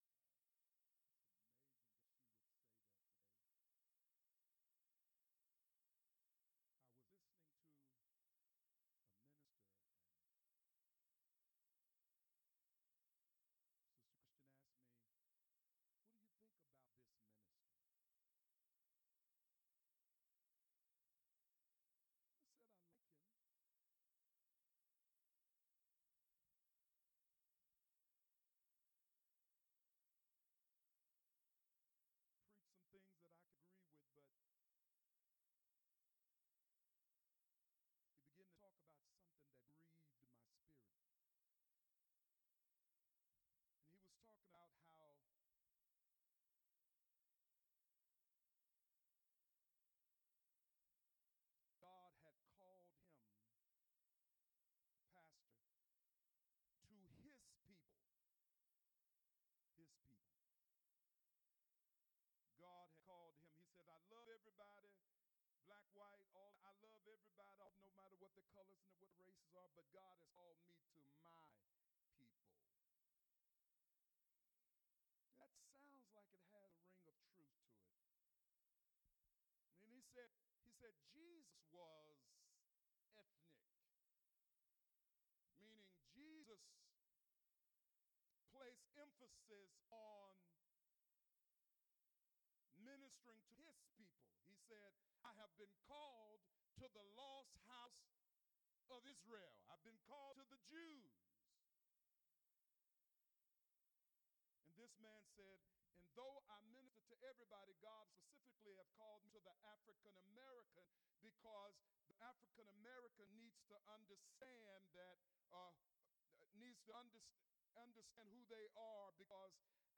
Sermons - Bountiful Blessings World Fellowship